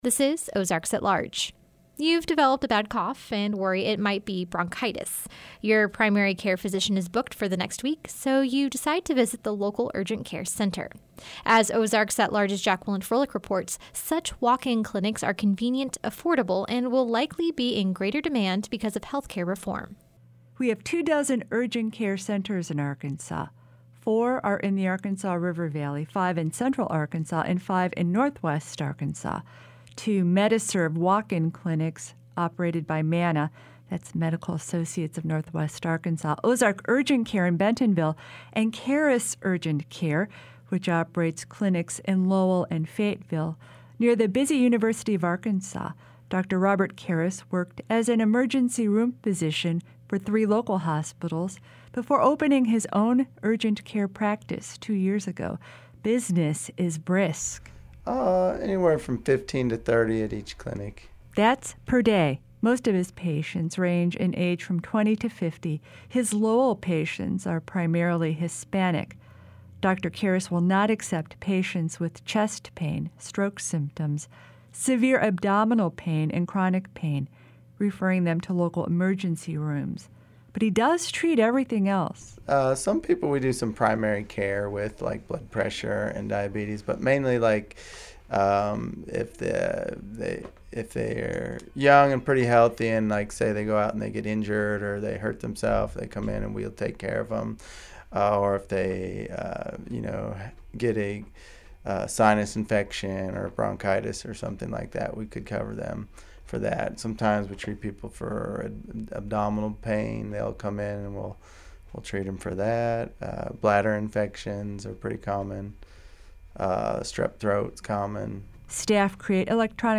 But with millions more Americans enrolling onto the health insurance marketplace--and fewer primary care physicians available to see them--urgent care clinics may fill a critical gap. We visit with urgent care provider